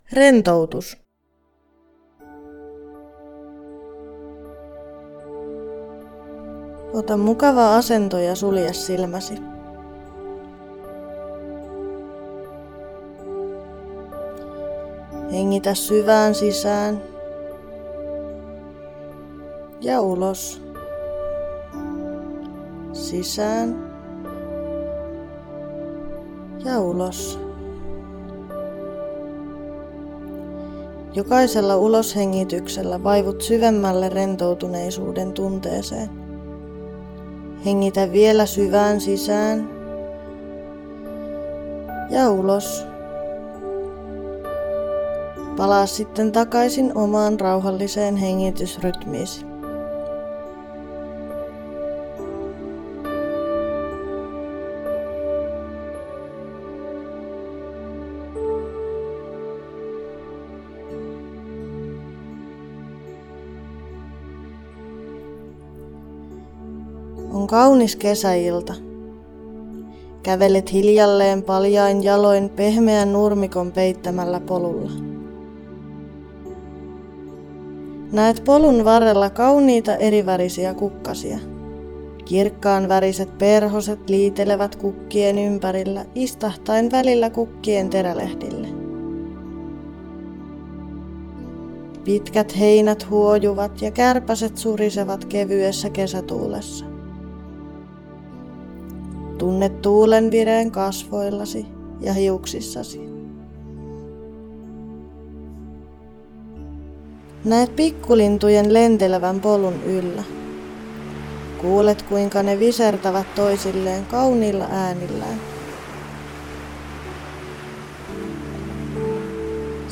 TOM-hankkeessa on valmistettu Muistityökirja "Muistin tähäre" ja siihen liittyvä äänikirja.